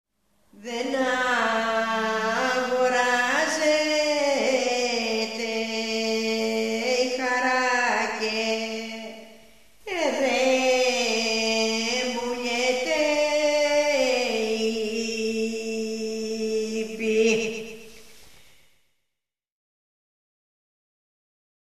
(παραδοσιακό της ξενιτειάς)